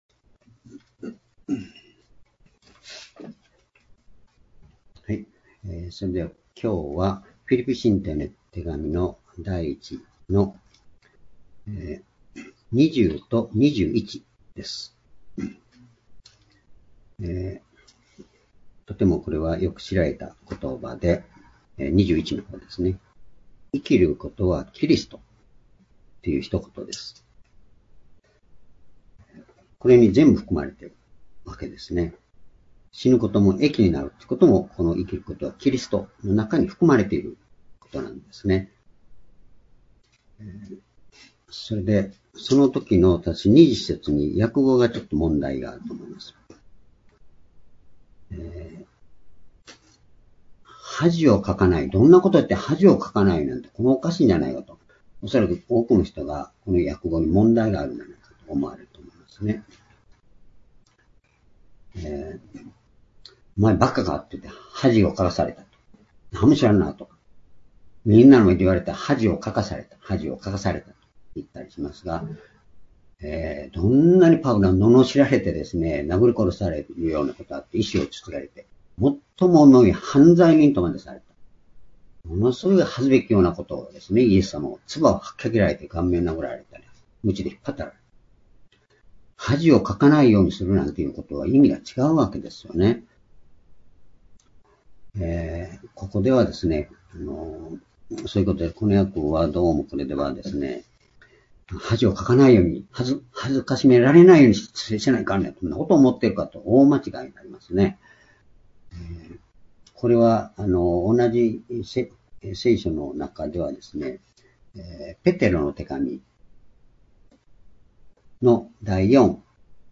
主日礼拝日時 2020年7月19日 聖書講話箇所 「生きることはキリスト」 フィリピ書 １章２０節～２１節 ※視聴できない場合は をクリックしてください。